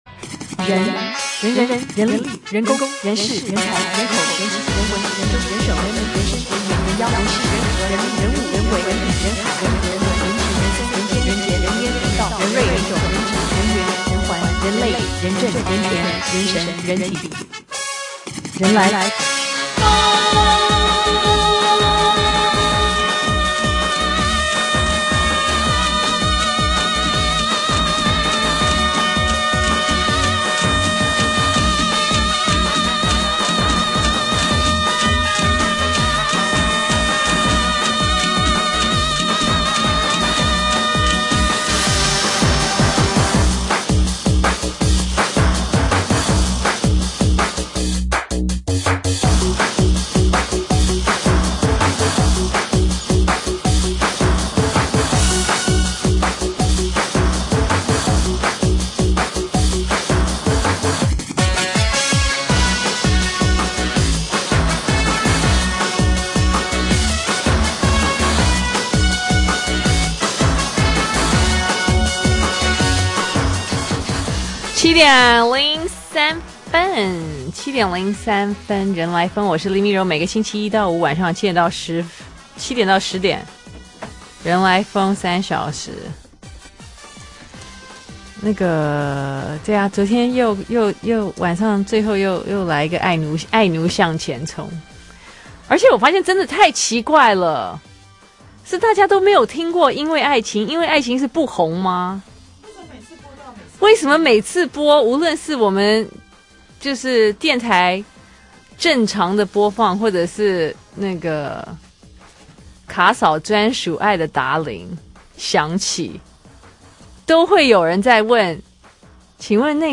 專訪